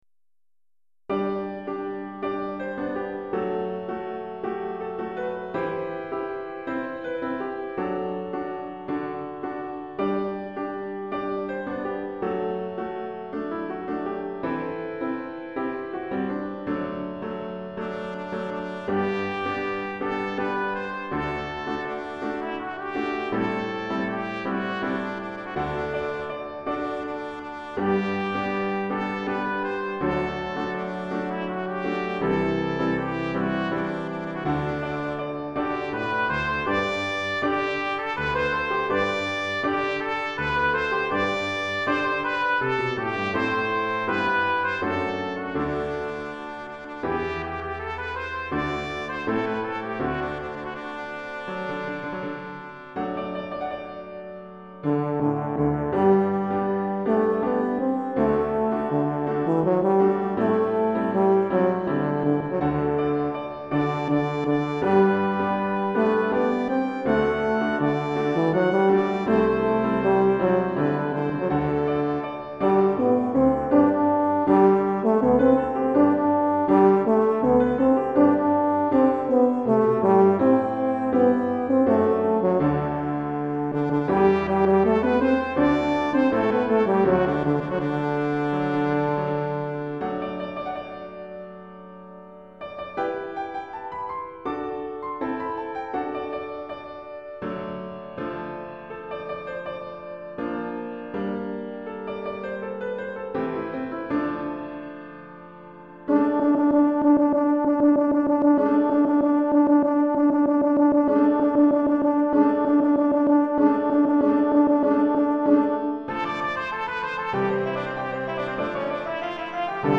Trompette en Sib et Euphonium en Sib Clé de Sol et